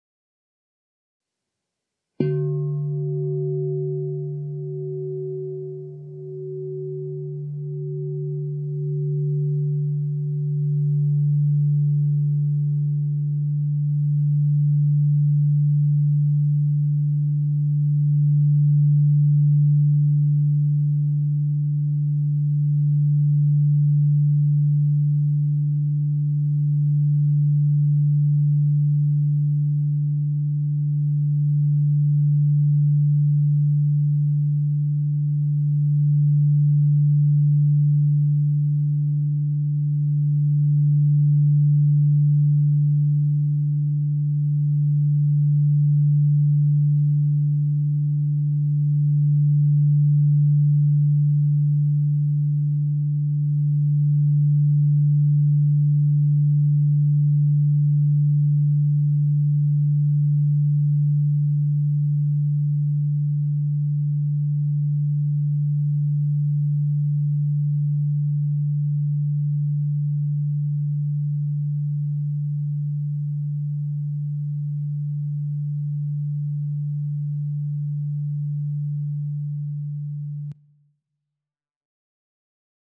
Meinl Sonic Energy 16" white-frosted Crystal Singing Bowl D3, 440 Hz, Sakralchakra (CSBM16D3)
Die weiß-matten Meinl Sonic Energy Crystal Singing Bowls aus hochreinem Quarz schaffen durch ihren Klang und ihr Design eine sehr angenehme…